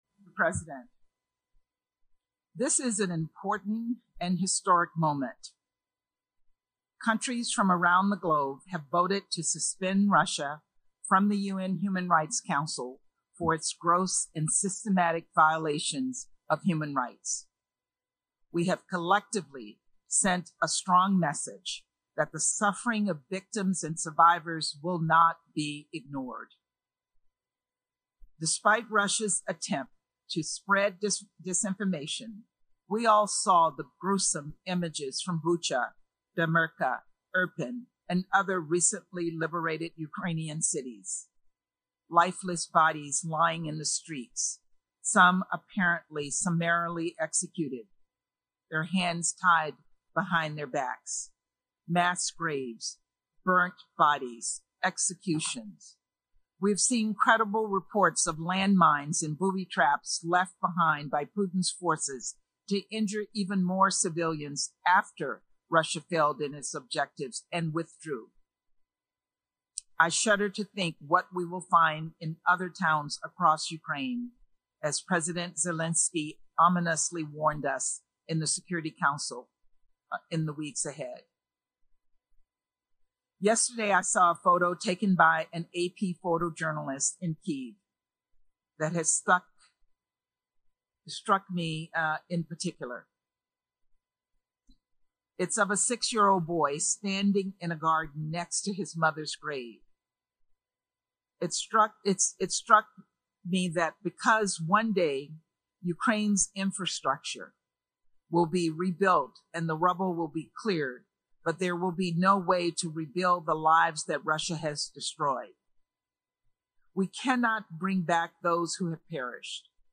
delivered 7 April 2002, UN Headquarters, New York, NY
Audio Note: Enhancement effects limited by compression at source